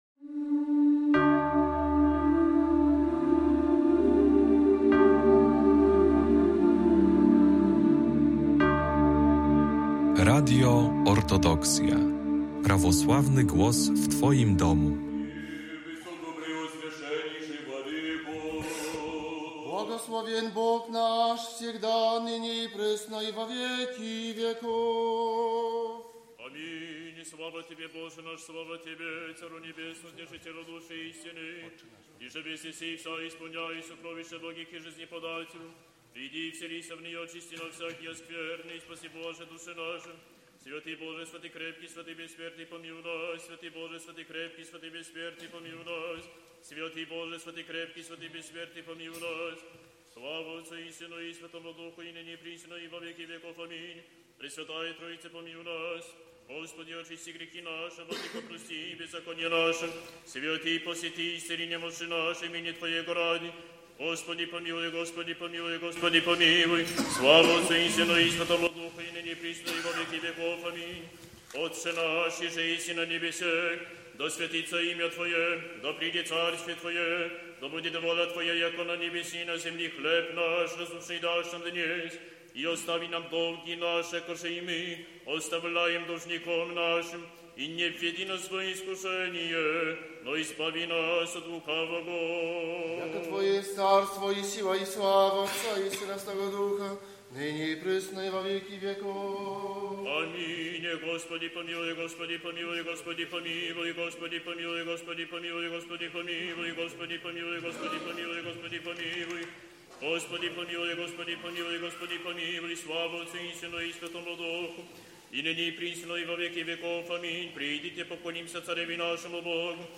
Nagranie Wielkiej Wieczerni z obrzędem przebaczenia win